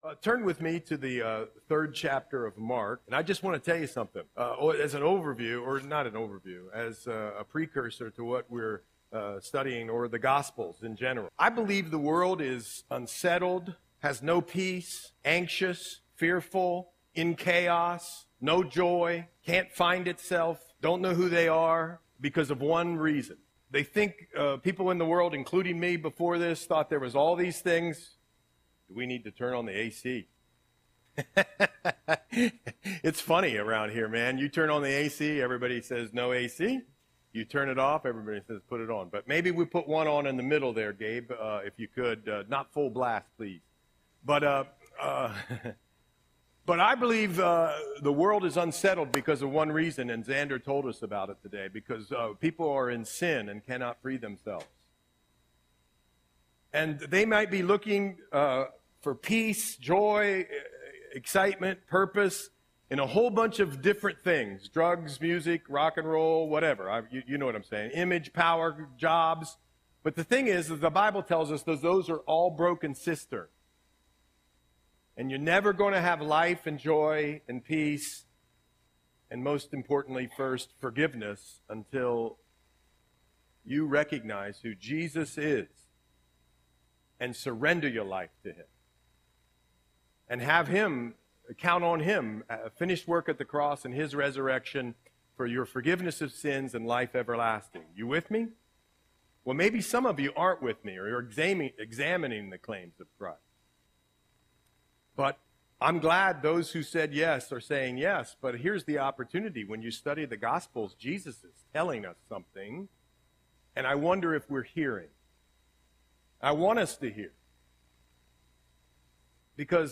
Audio Sermon - November 17, 2024